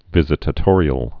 (vĭzĭ-tə-tôrē-əl)